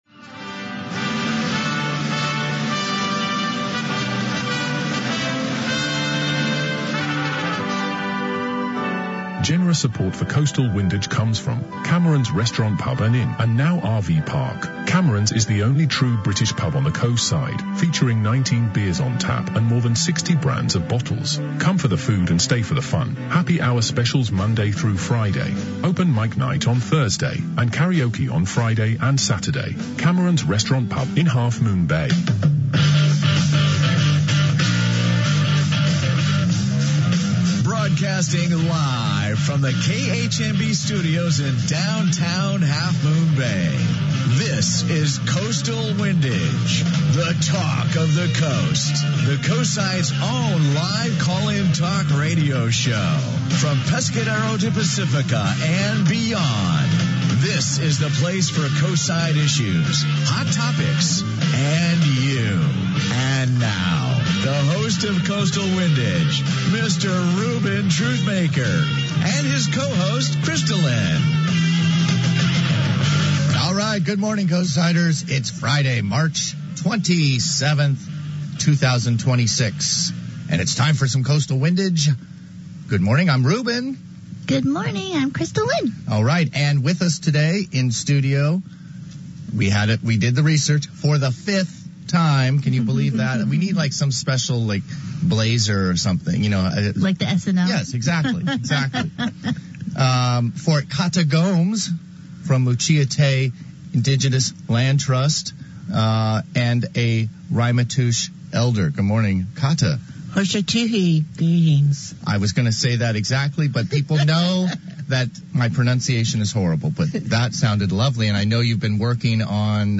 The Coastside’s only live, call-in talk radio show, Coastal Windage features Coastside issues, hot topics, and live comments from Coastsiders in real time.